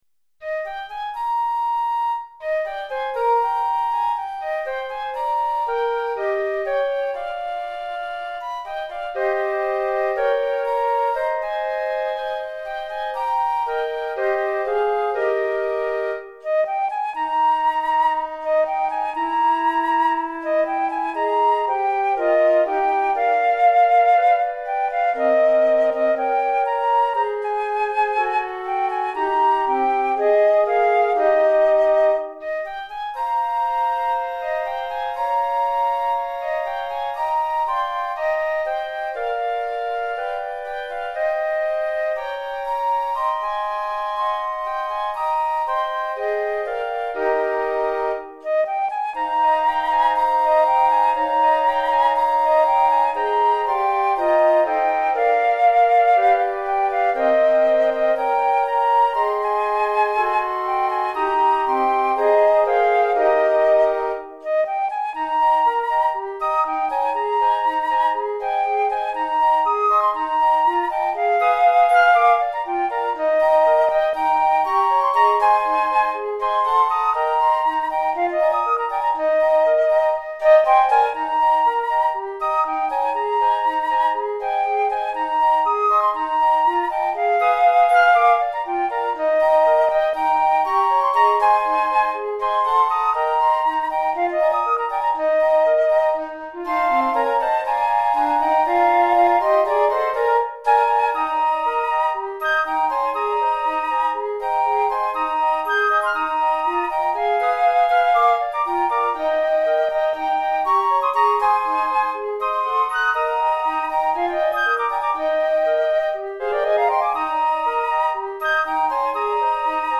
5 Flûtes Traversières